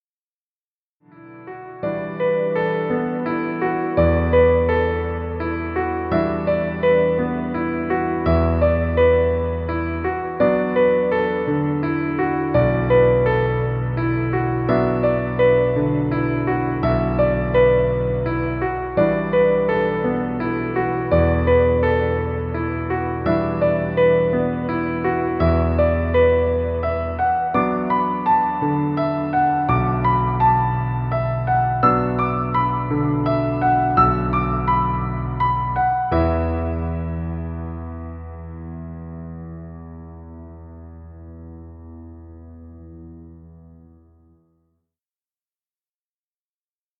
Piano music. Background music Royalty Free.
Stock Music.